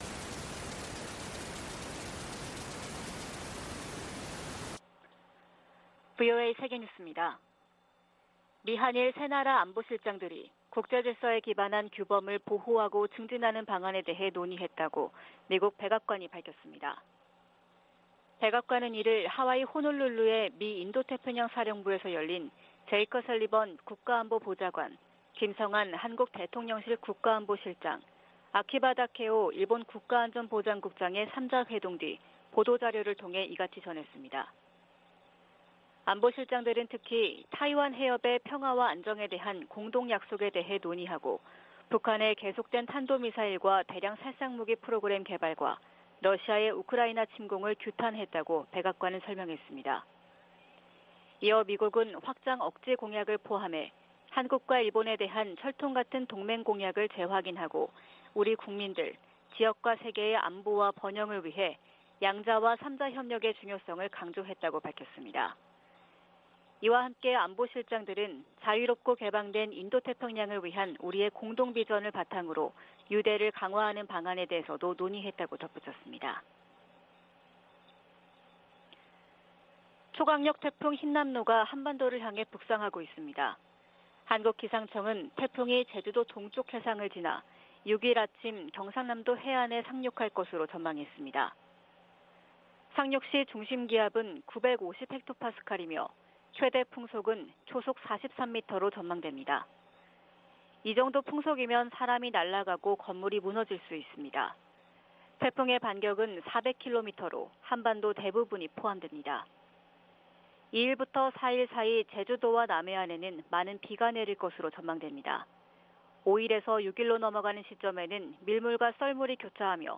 VOA 한국어 '출발 뉴스 쇼', 2022년 9월 3일 방송입니다. 미국과 한국, 일본 안보실장들이 하와이에서 만나 북한 미사일 프로그램을 규탄하고 타이완해협 문제 등을 논의했습니다. 미국과 한국 간 경제 협력을 강화하기 위한 미 의원들의 움직임이 활발해지고 있습니다. 미국 검찰이 북한의 사이버 범죄 자금에 대한 공식 몰수 판결을 요청하는 문건을 제출했습니다.